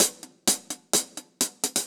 Index of /musicradar/ultimate-hihat-samples/128bpm
UHH_AcoustiHatC_128-03.wav